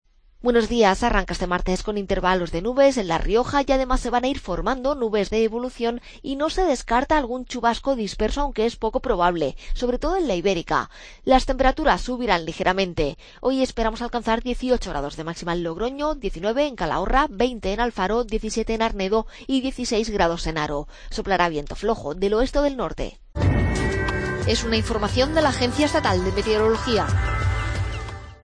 AUDIO: Pronóstico. Agencia Estatal de Meteorología.